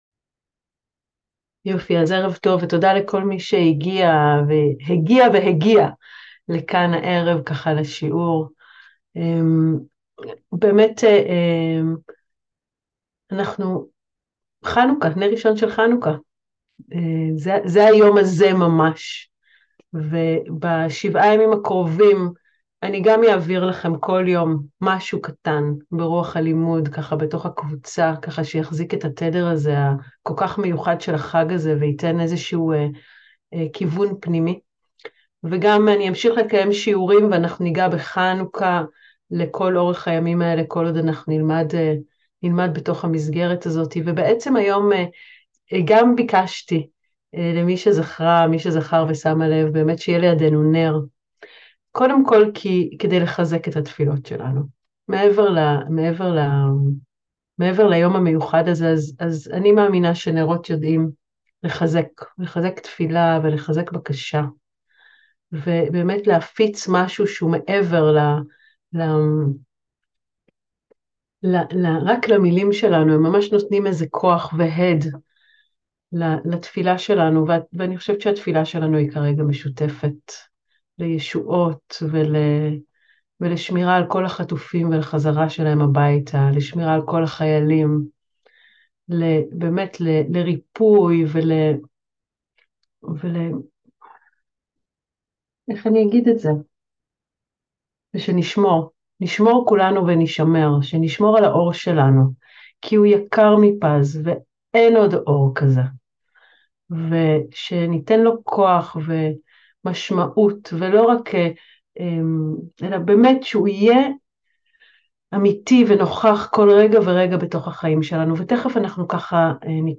הלומדות שיעור מיוחד לחנוכה